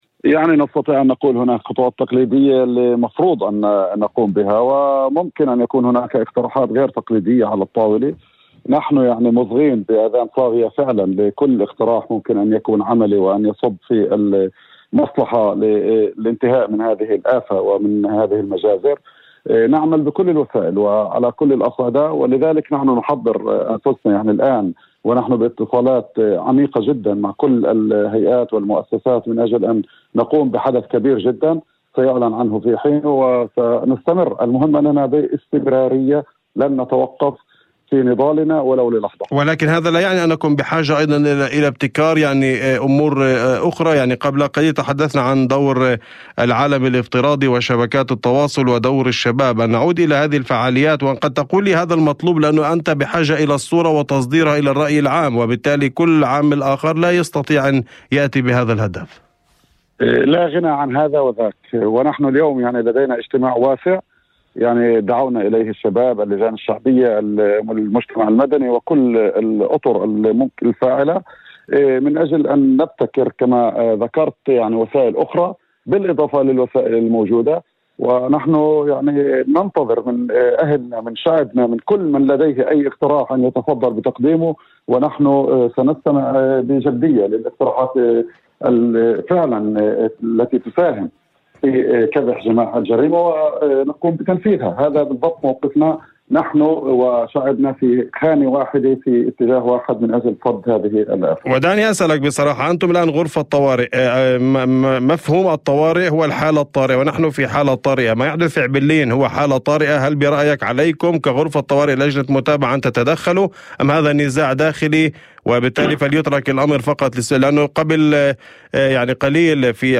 في مداخلة هاتفية